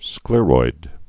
(sklîroid)